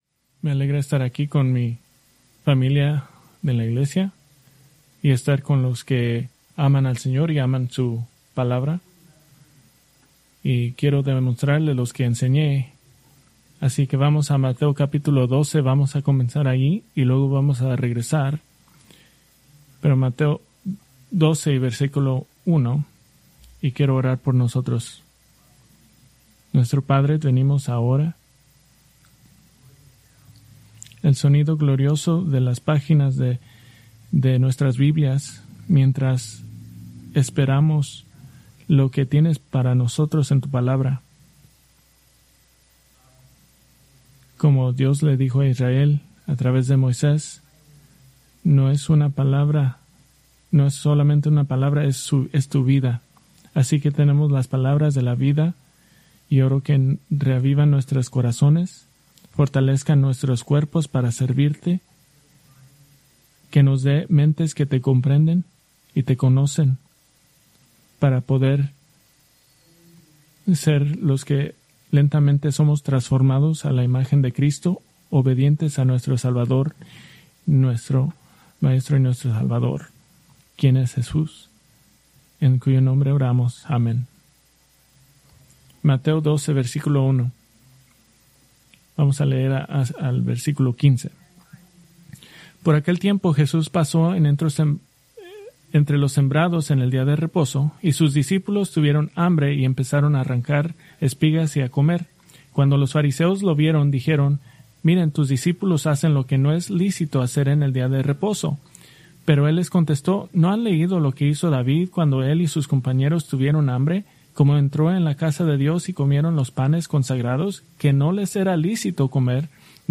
Preached November 2, 2025 from Mateo 12:1-14